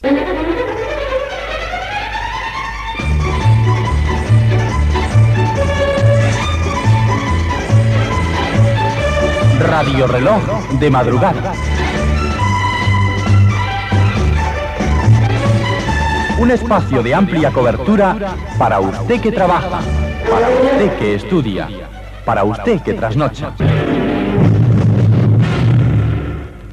Promo del programa